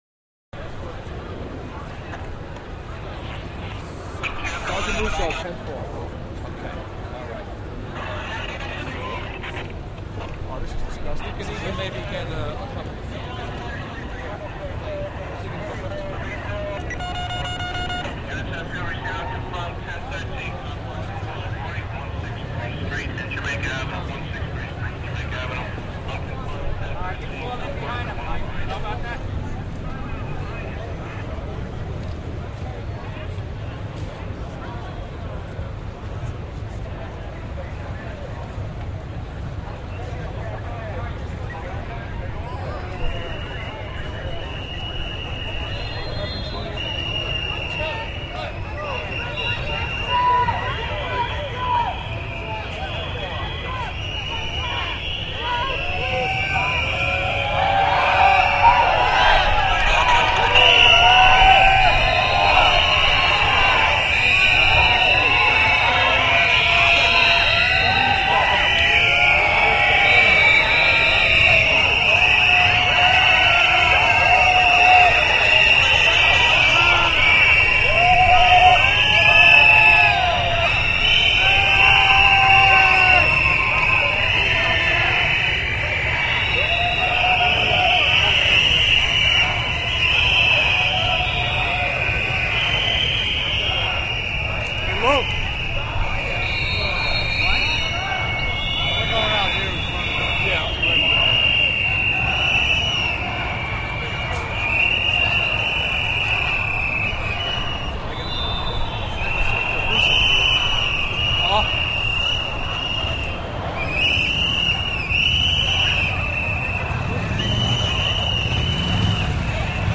Звуки катастрофы: Шум улицы после стихийного бедствия